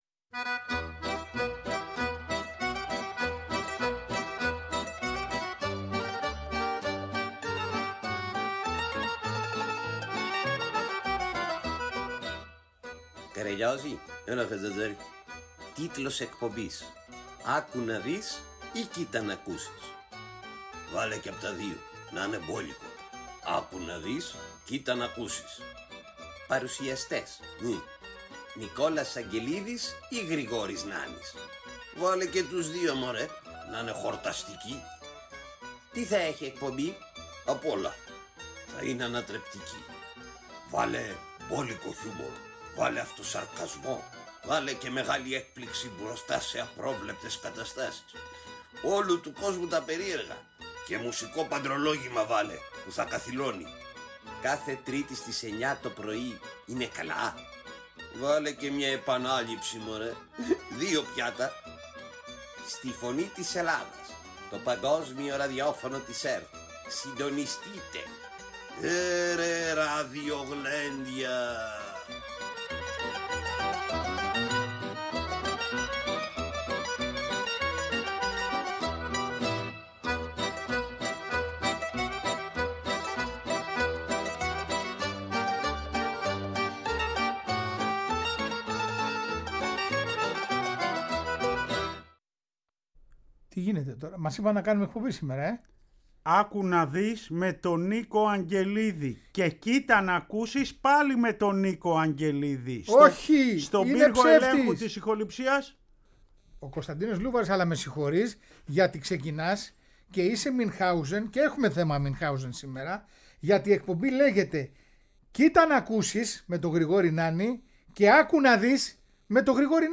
Τέλος μαθαίνουμε την ιστορία και ακούμε τον ύμνο της ΑΕ Καρλοβασίου που μόλις κυκλοφόρησε…